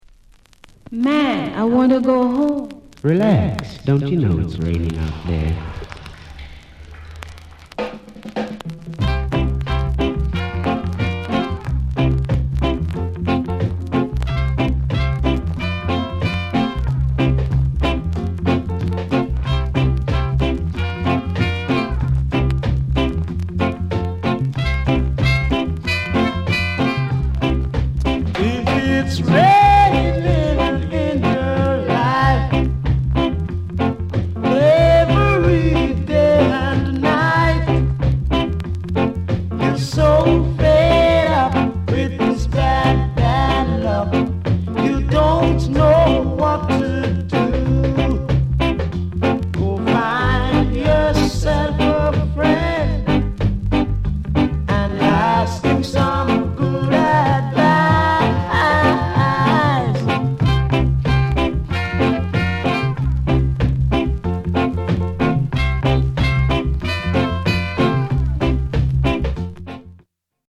ROCKSTEADY